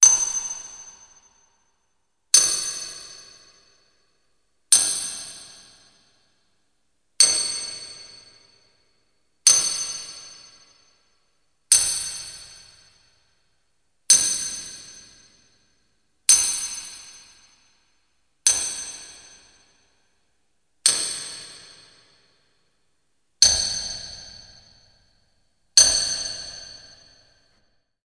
SFX打铁铸剑游戏动画技能释放UI交互音效下载